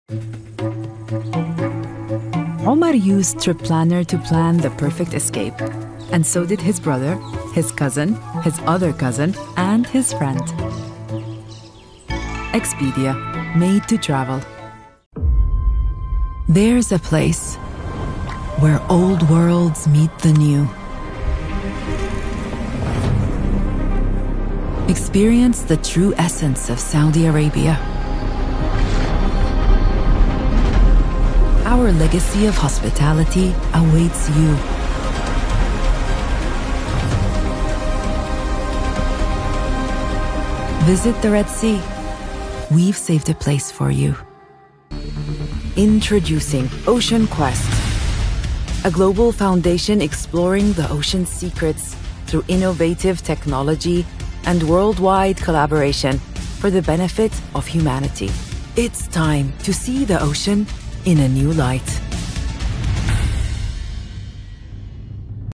Tief, Cool, Sanft
Kommerziell